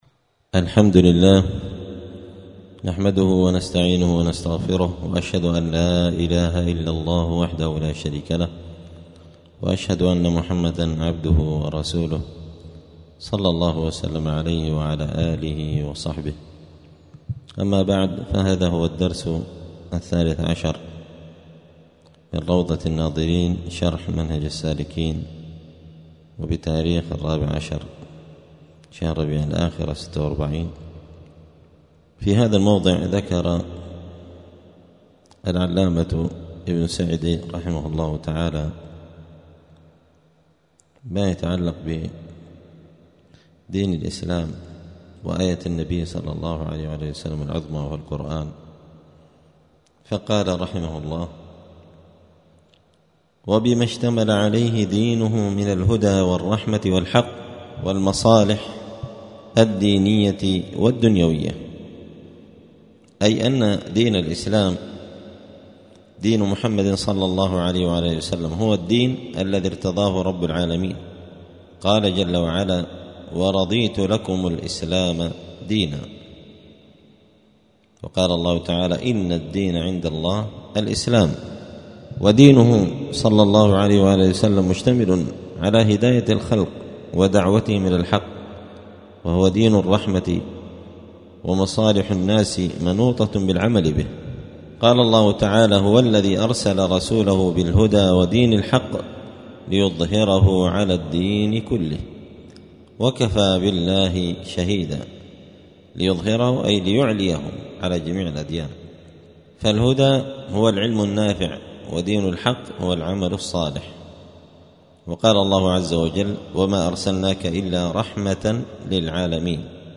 *الدرس الثالث عشر (13) {كتاب الطهارة دين الإسلام لا يقبل الله دينا سواه}*